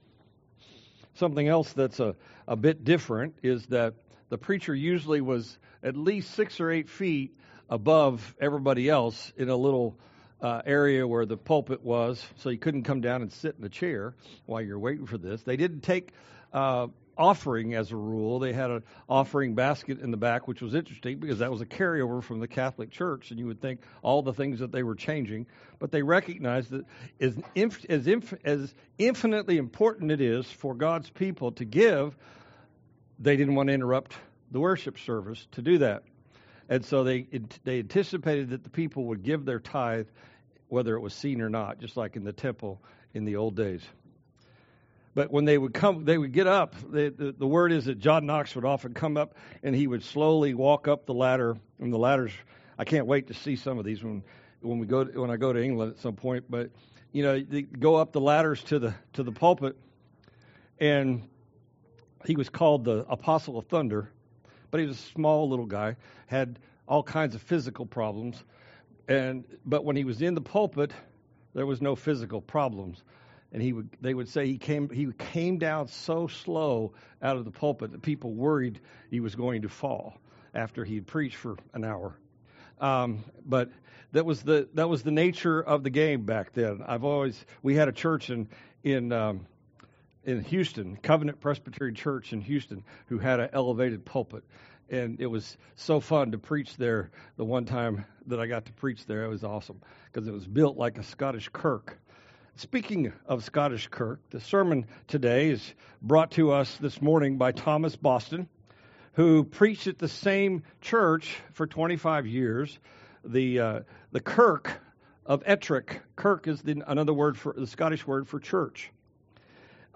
Reformation Sunday